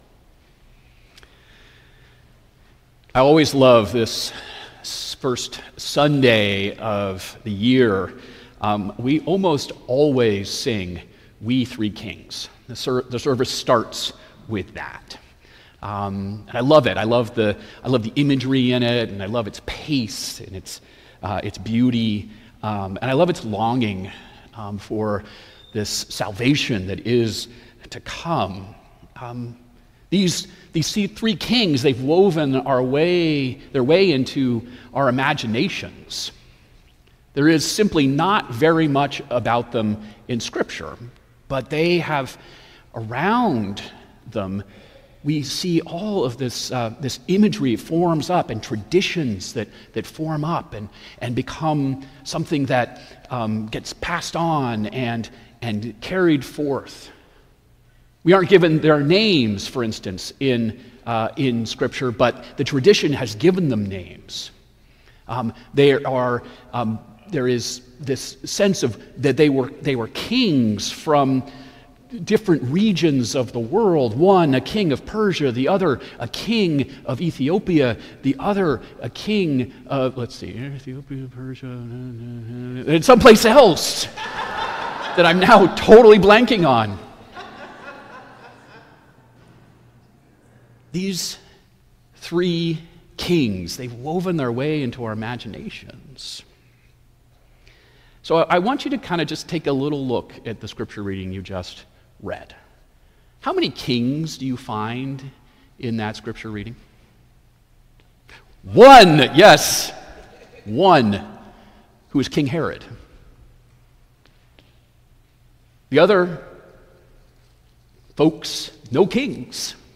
St. Augustine by-the-Sea Sermons